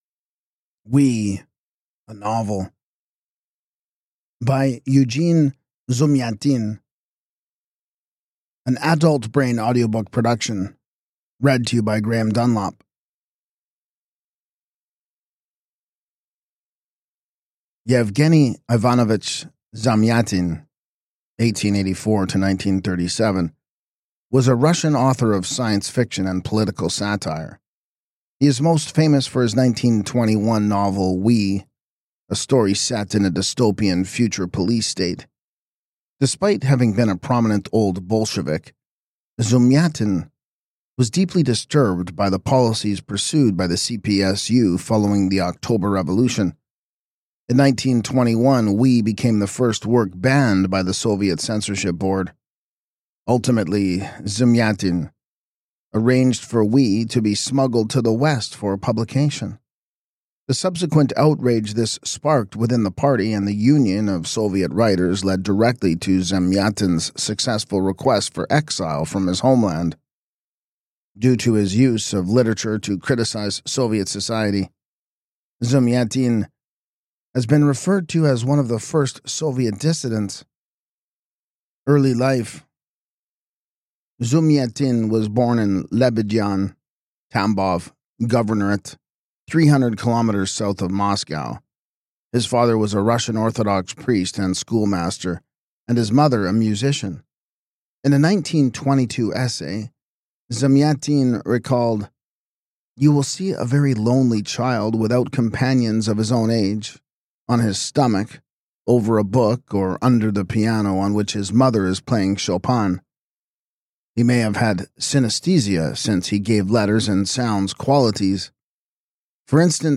Experience this groundbreaking novel in audiobook format, exclusively from Adultbrain Publishing.